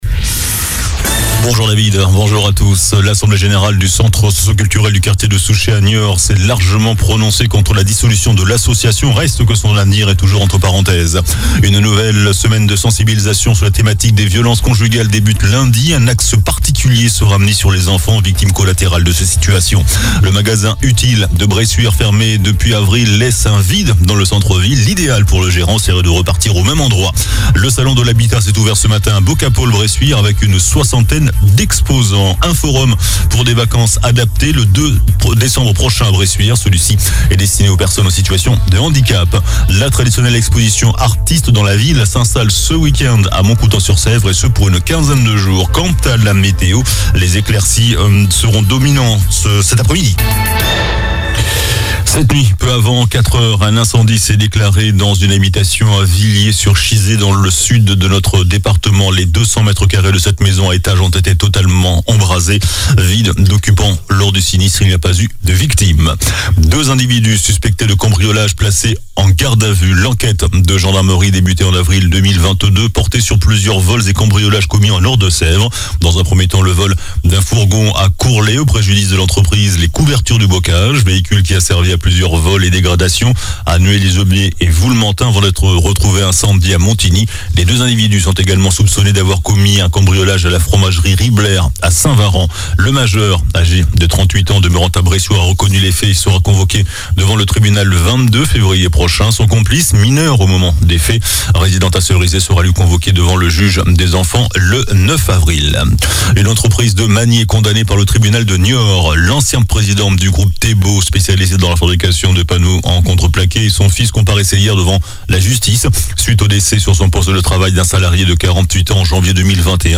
JOURNAL DU VENDREDI 17 NOVEMBRE ( MIDI )